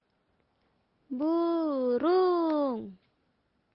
burung.mp3